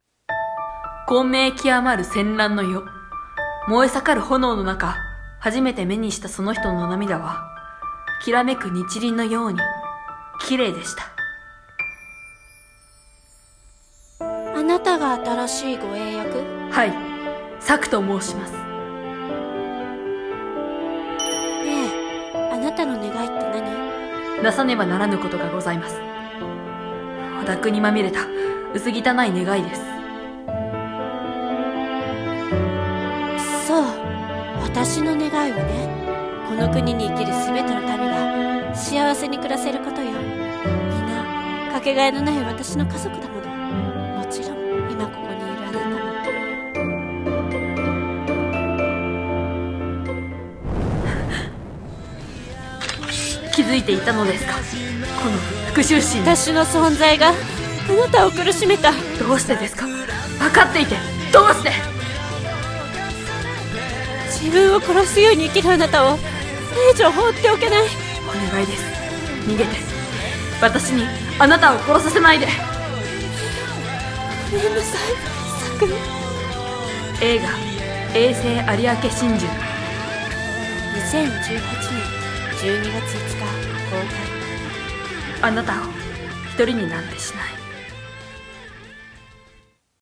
【CM風声劇台本】永世有明心中